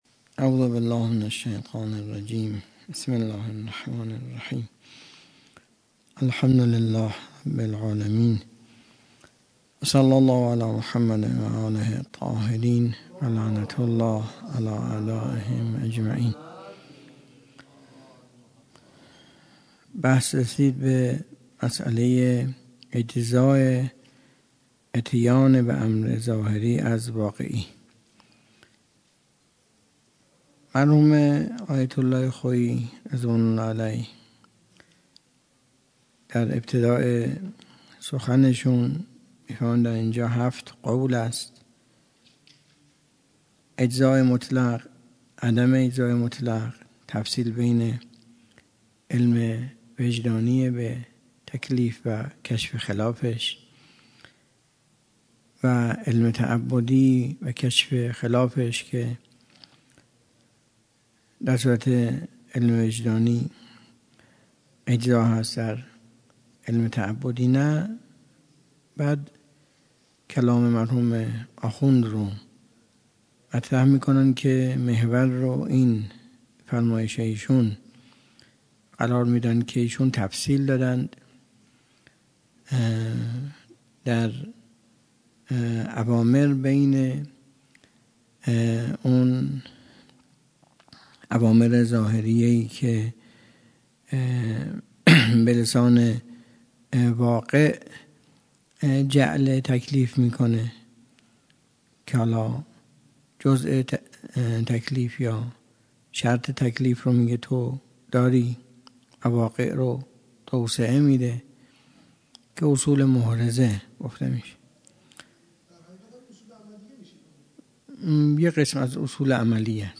درس خارج
سخنرانی